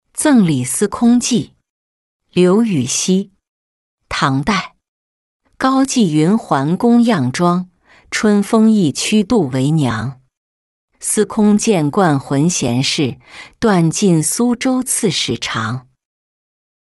赠李司空妓-音频朗读